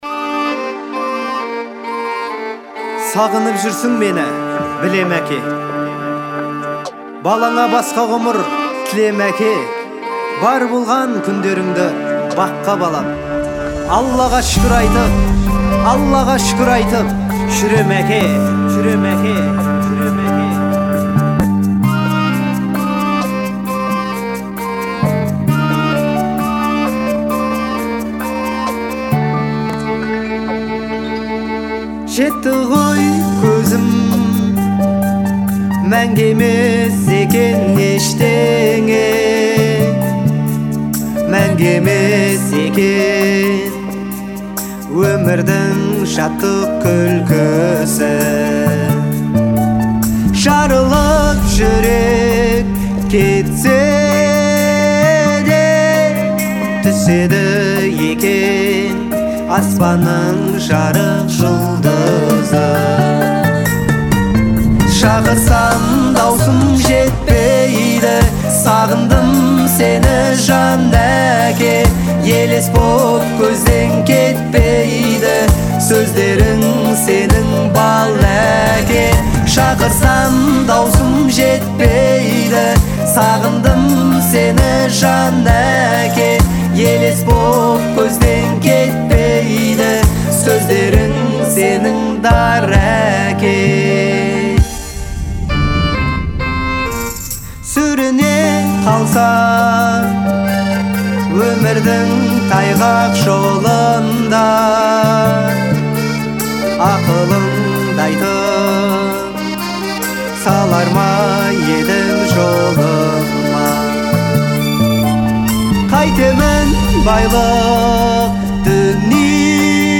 это трогательная песня в жанре казахской народной музыки